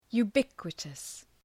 Προφορά
{ju:’bıkwətəs}